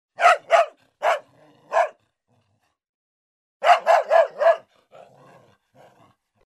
Звуки лая шпица
Дворняга подала голос